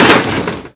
object break sounds
metalbreak.ogg